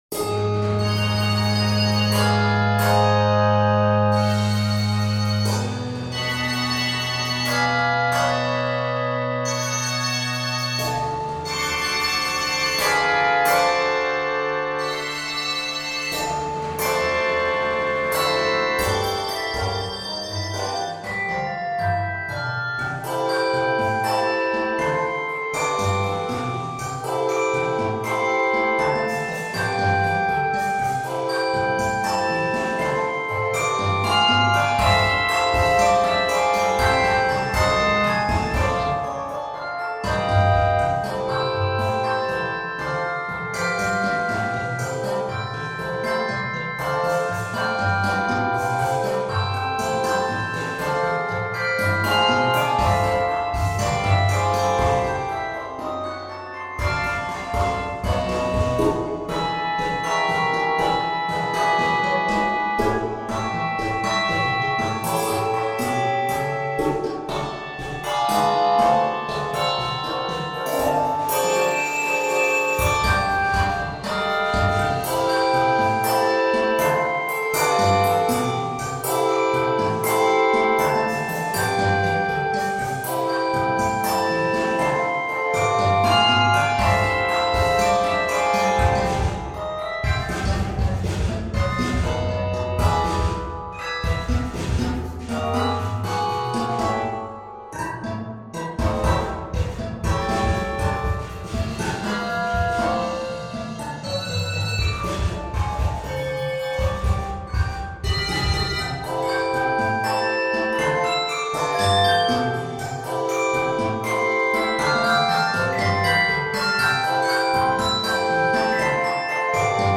giving new meaning to the term syncopation.